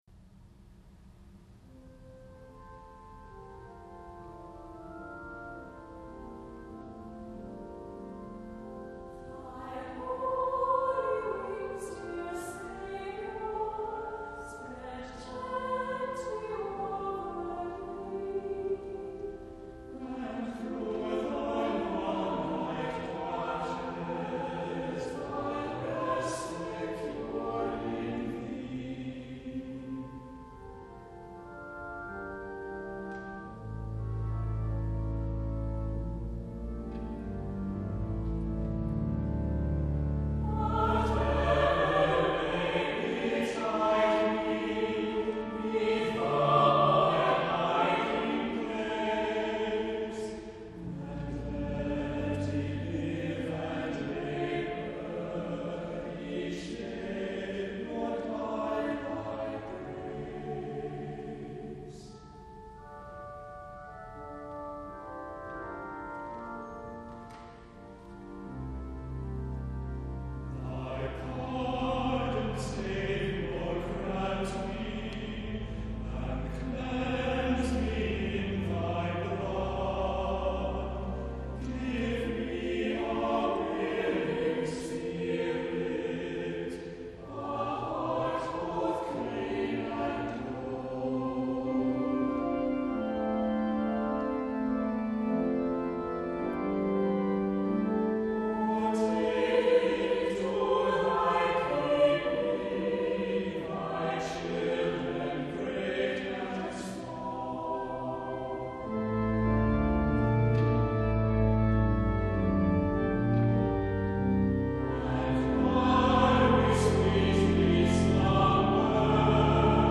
* gentle, soaring hymn-anthem for Pentecost
* elegant vocal and organ lines, gracefully interwoven